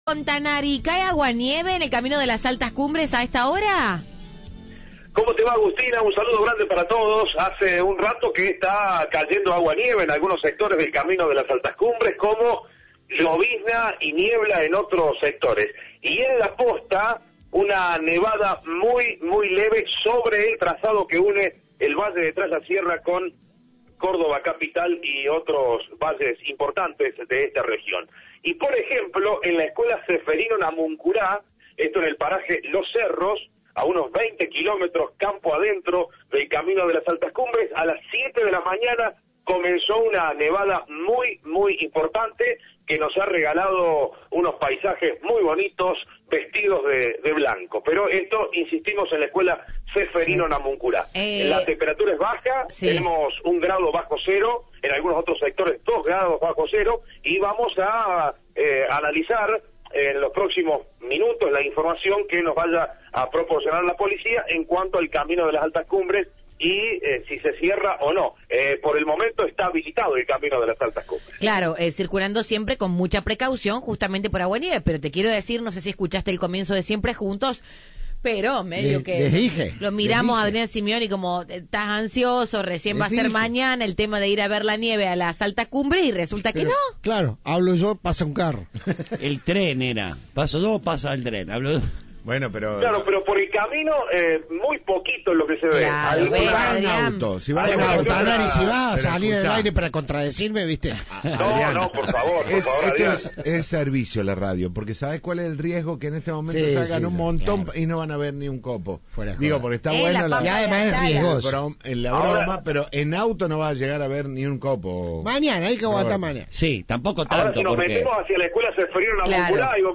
Informe de